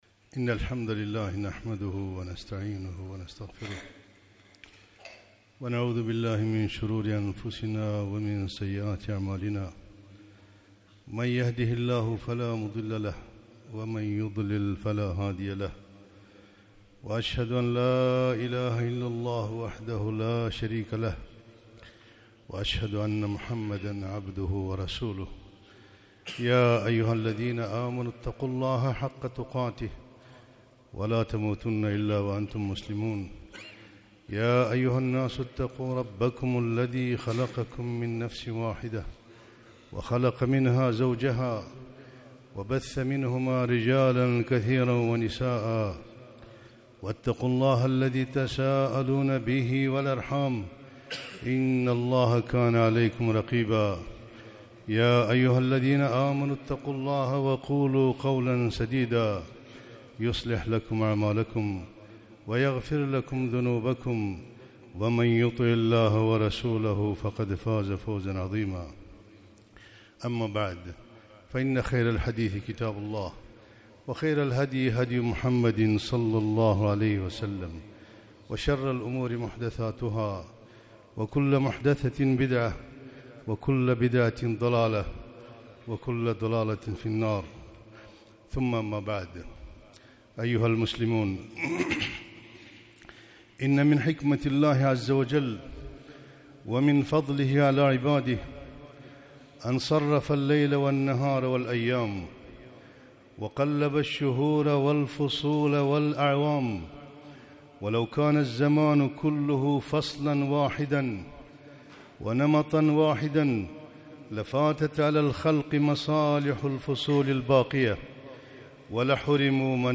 خطبة - أحكام الشتاء